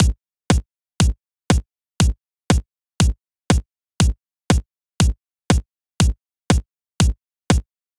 Session 08 - Kick.wav